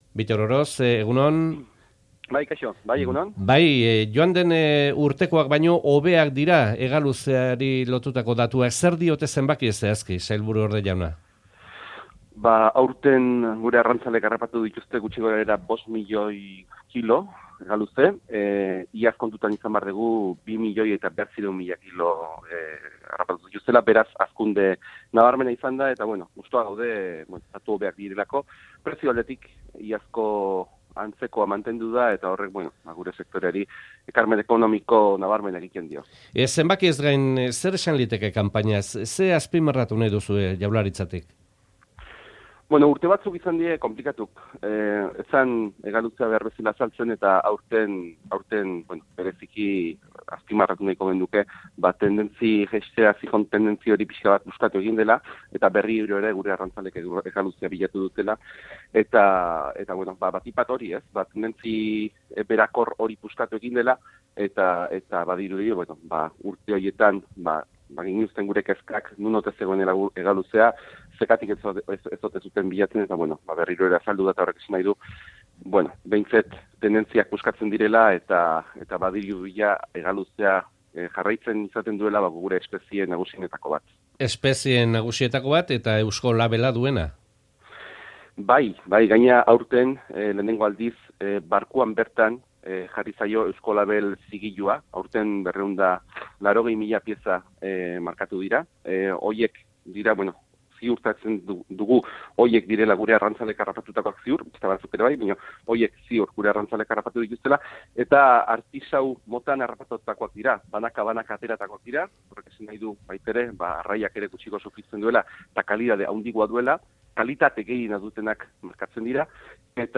Euskadi Irratia | Goiz Kronika| Elkarrizketa Bittor Oroz